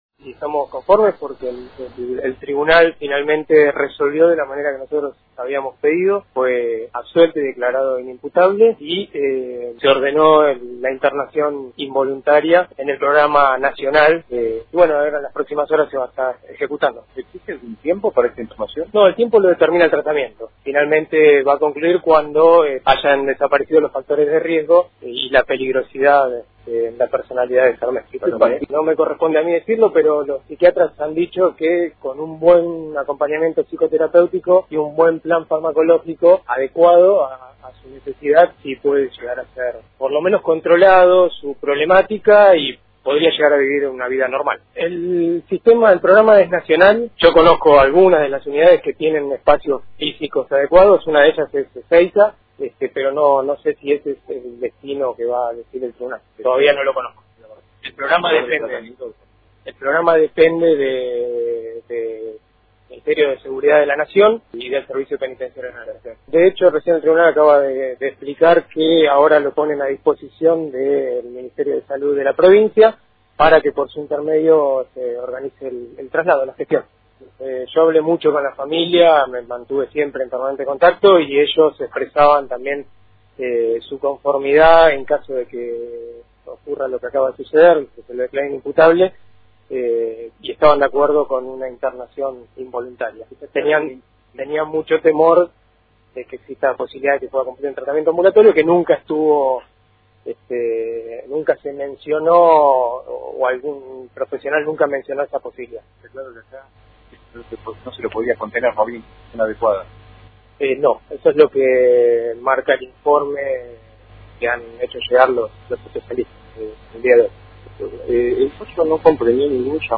El fiscal Eduardo Tepedino dialogó con Radio Fueguina minutos después de conocido el fallo y se mostró conforme por lo sancionado por el Tribunal, y aclaró que el traslado a esta unidad fuera de la provincia de Tierra del Fuego (podría ser Ezeiza) se decidirá en las próximas horas.